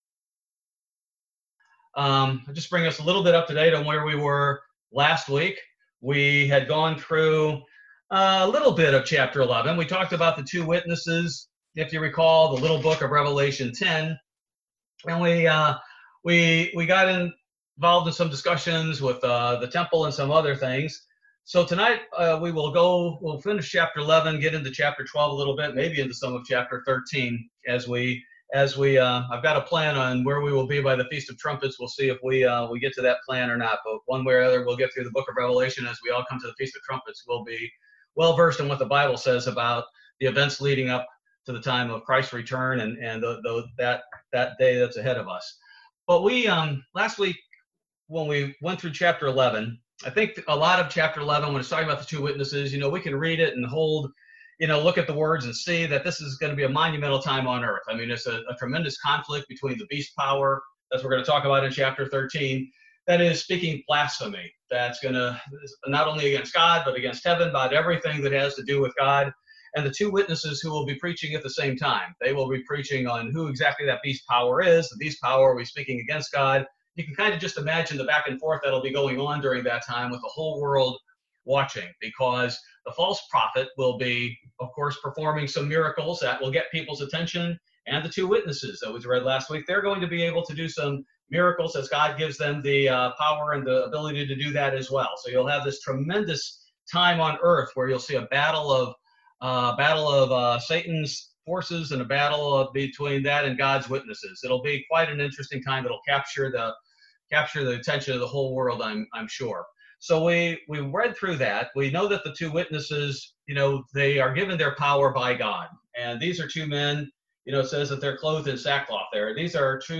Bible Study - September 9, 2020